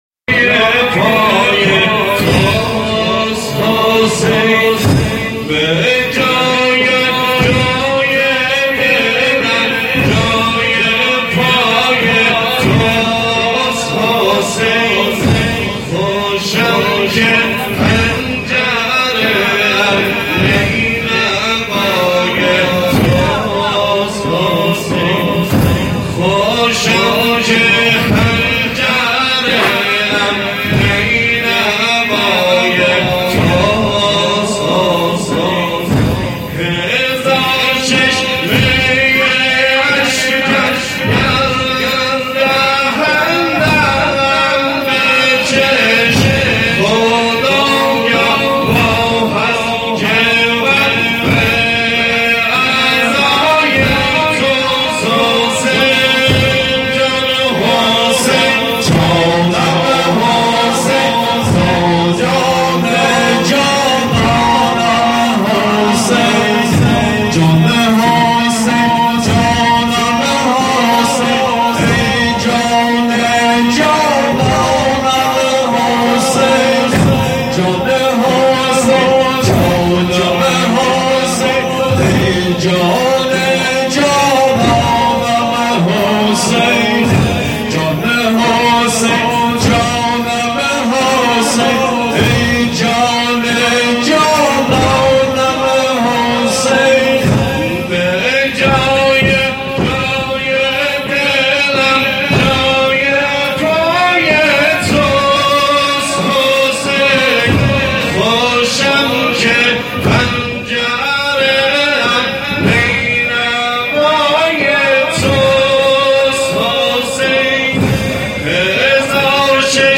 محرم ۹۶(زنجیر زنی)